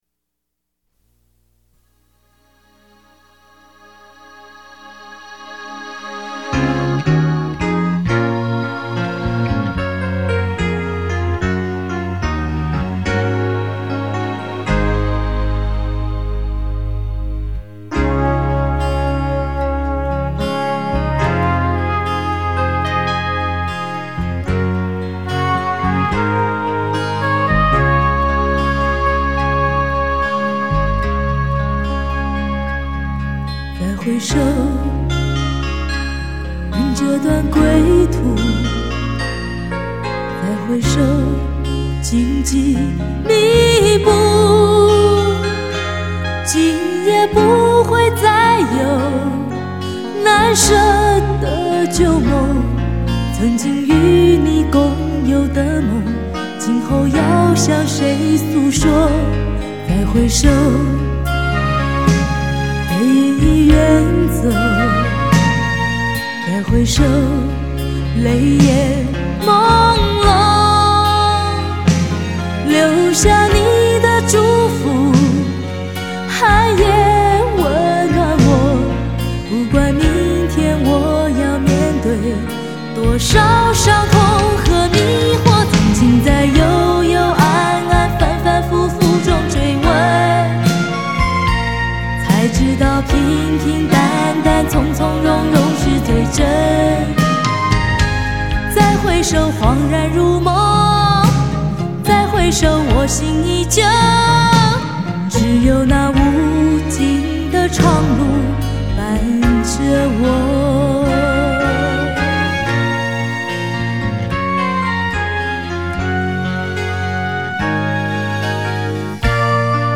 专辑类型：流行音乐